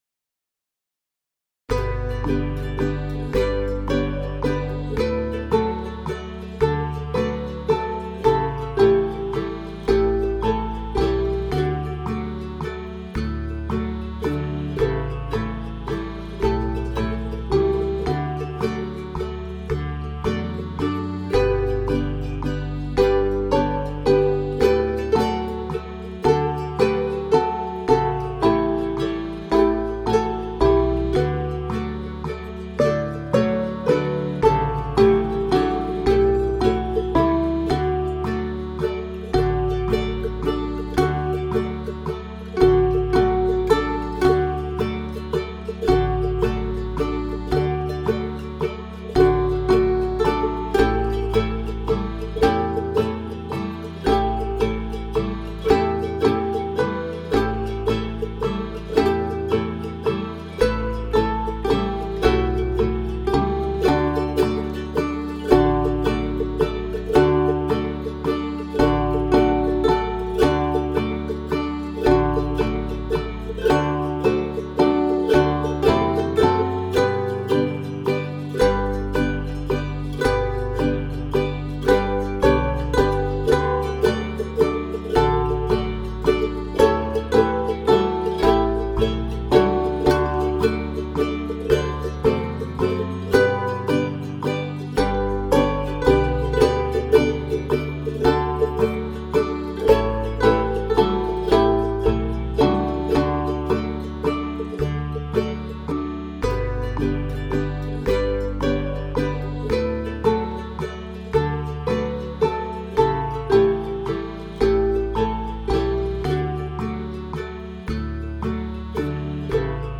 Pieza para timple.